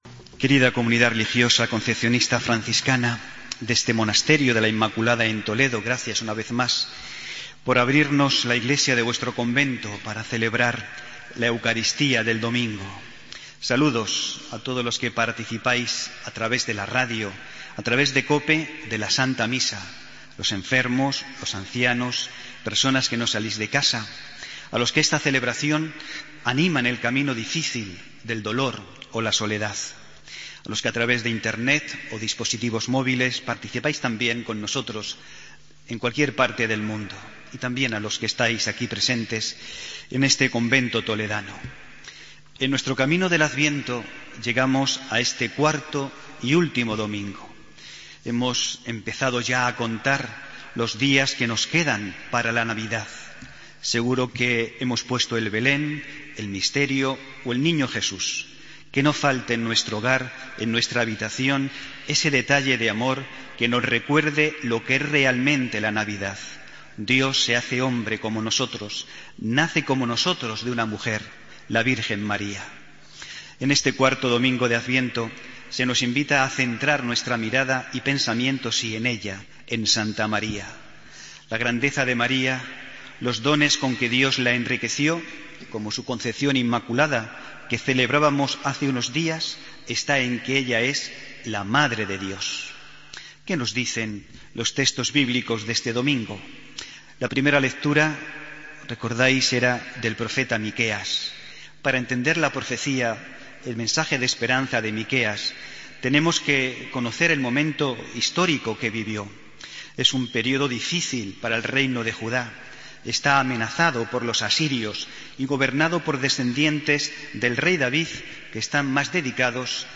Homilia del domingo 20 de diciembre